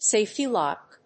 sáfety lòck